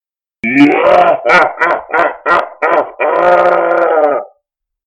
Evil Man Laugh
Category 🤣 Funny
creepy freaky horror old string sound effect free sound royalty free Funny